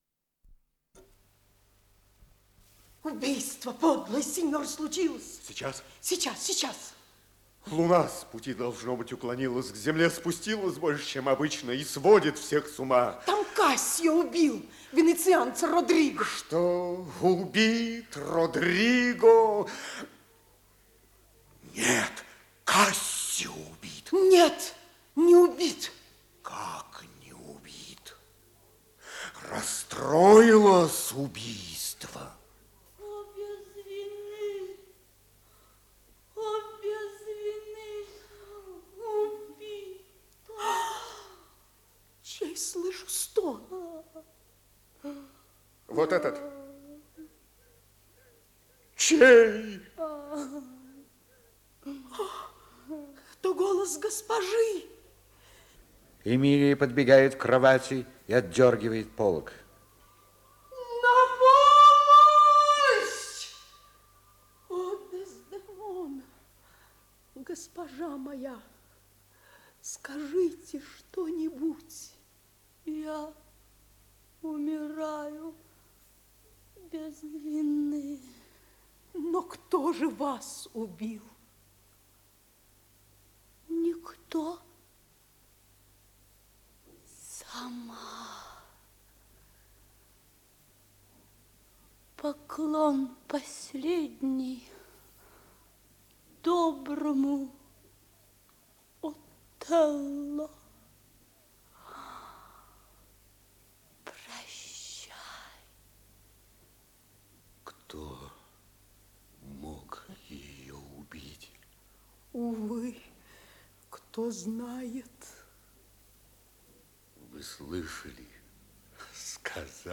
Исполнитель: Артисты театра им. Моссовета